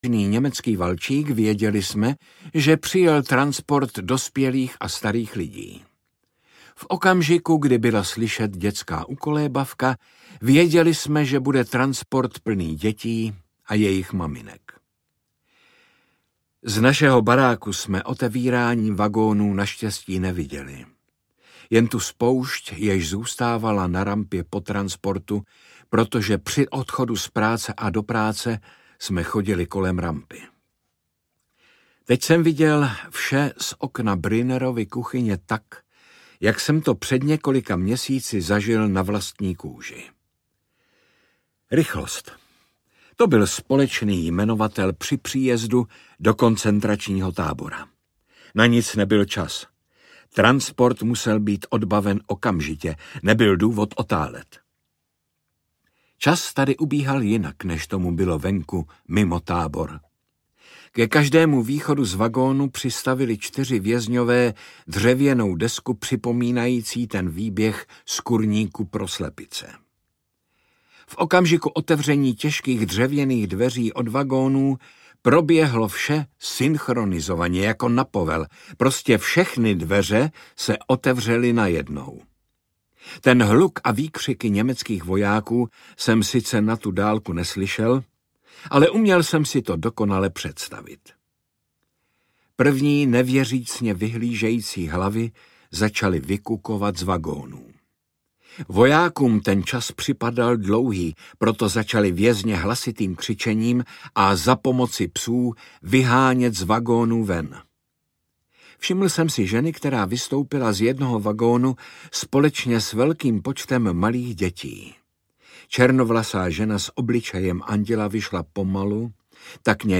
Návrat z pekla audiokniha
Ukázka z knihy
Čte Jaromír Meduna.
Vyrobilo studio Soundguru.
• InterpretJaromír Meduna